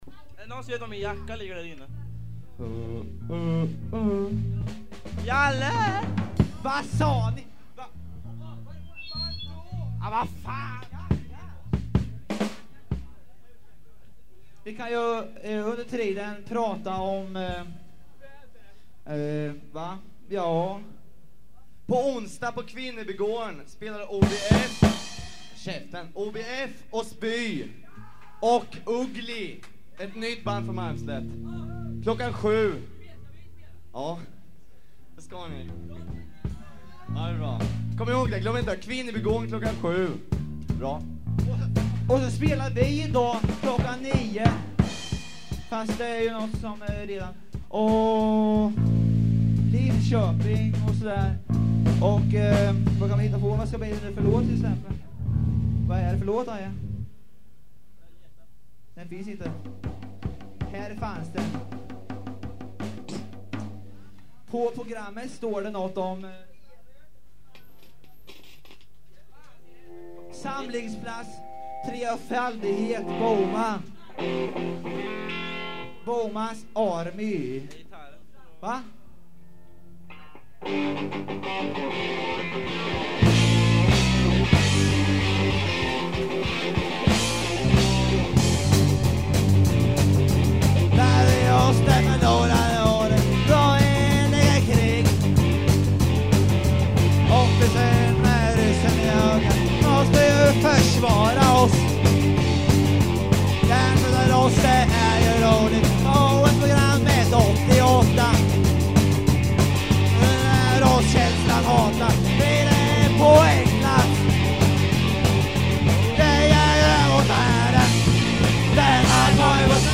Guitar
Bass
Drums
Voice
Live at Kannan 1980